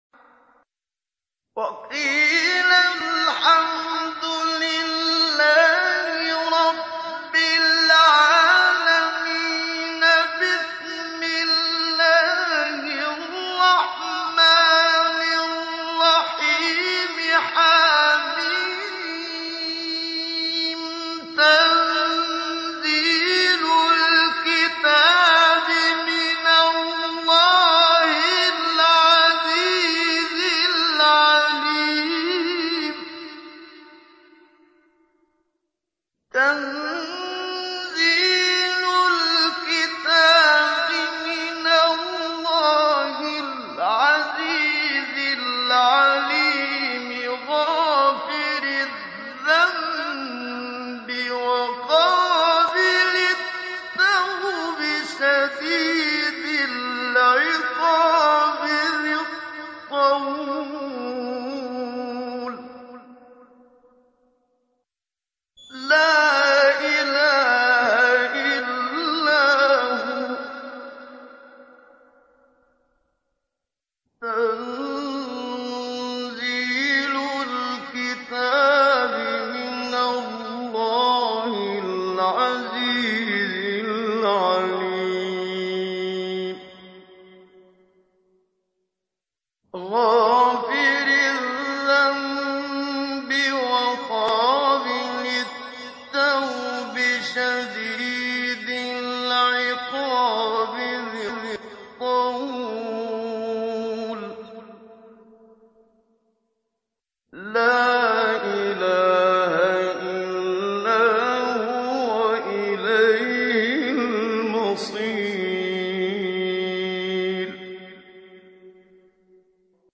تحميل : 40. سورة غافر / القارئ محمد صديق المنشاوي / القرآن الكريم / موقع يا حسين